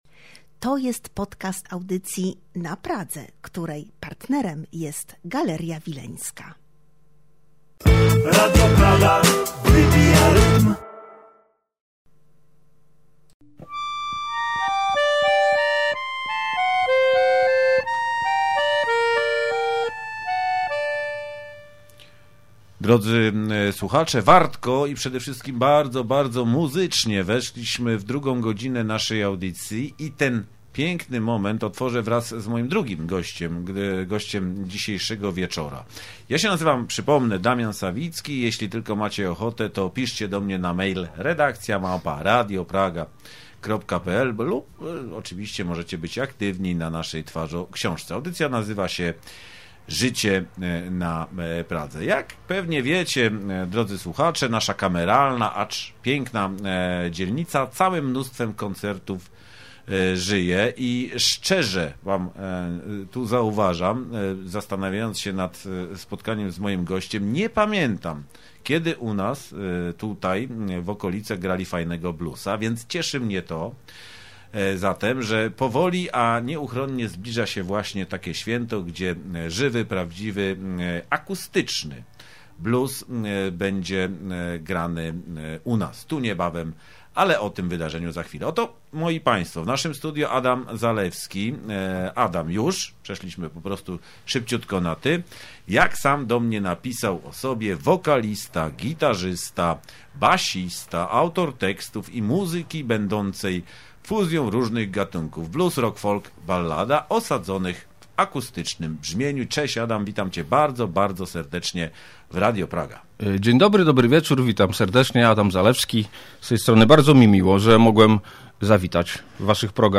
Wszystko to okraszone muzyką mojego gościa.